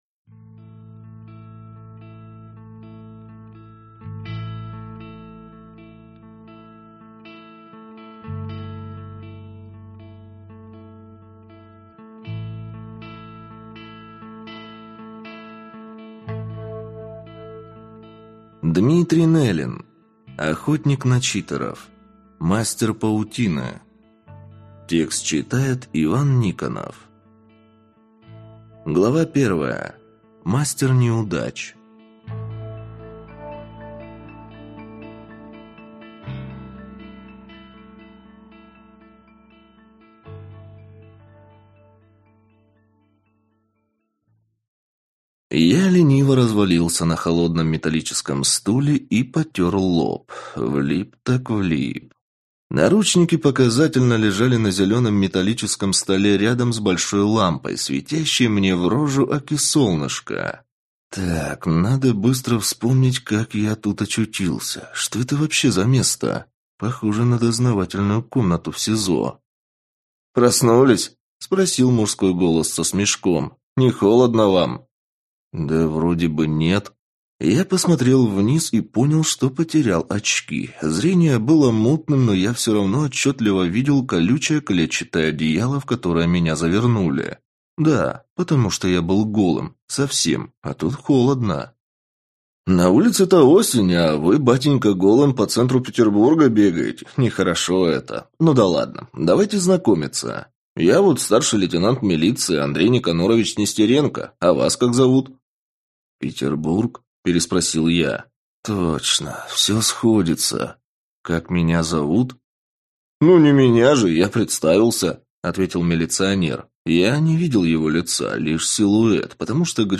Аудиокнига Мастер паутины | Библиотека аудиокниг